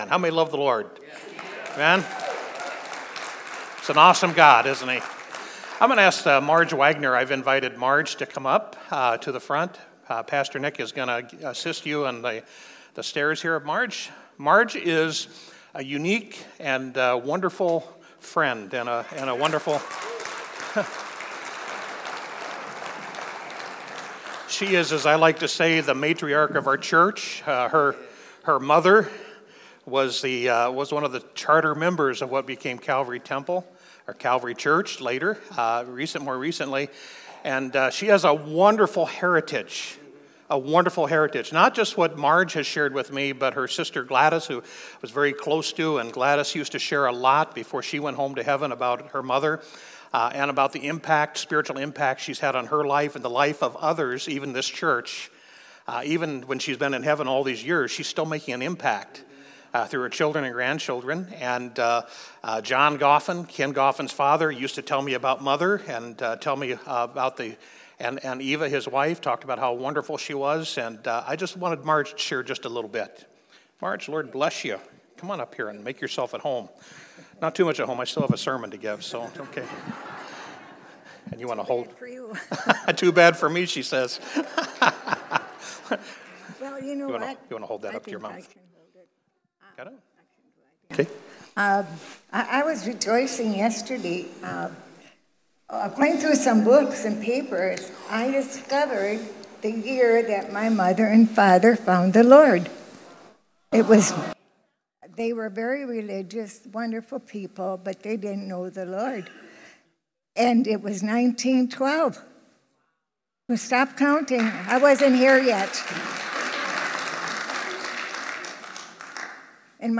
Mother's Day Sermon